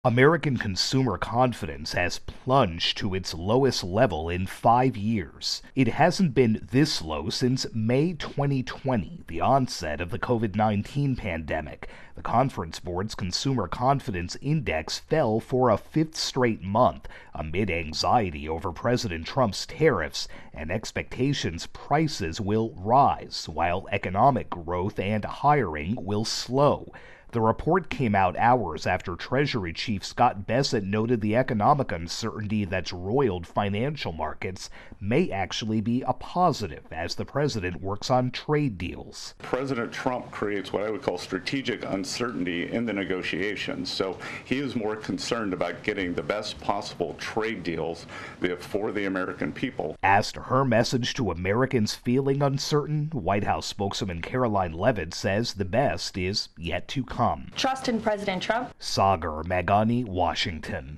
reports on a sharp drop in consumer confidence.